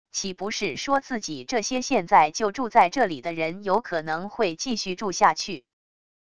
岂不是说自己这些现在就住在这里的人有可能会继续住下去wav音频生成系统WAV Audio Player